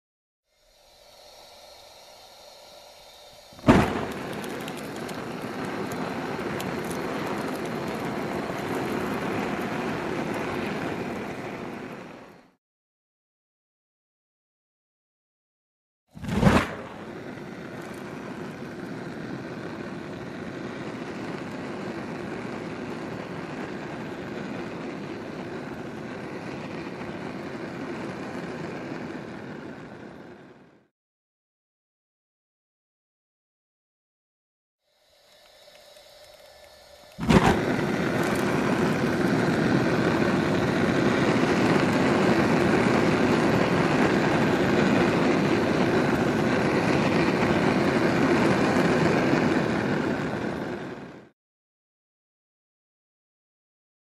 На этой странице собраны звуки работы горелок: от мягкого потрескивания до интенсивного горения.
Звук зажигающейся и гаснущей газовой горелки